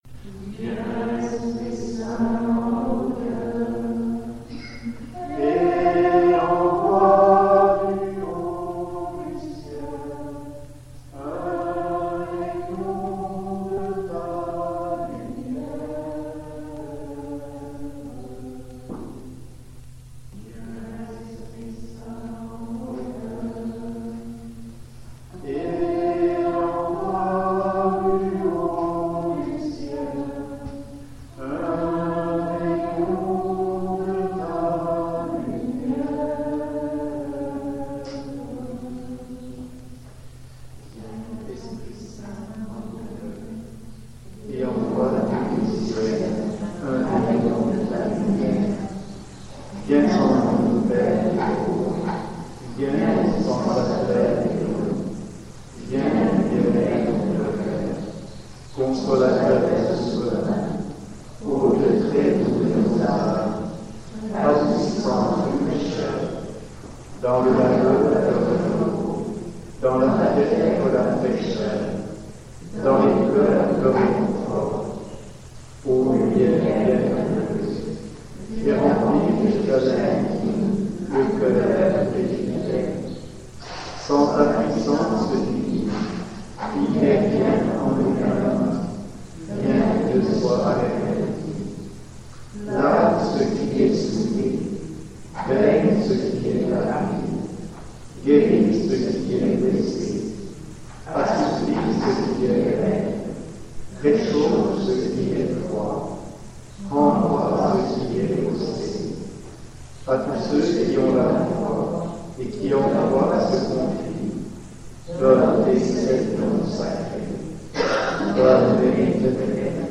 6_le_oui_de_marie_retraite_juin_1994_.mp3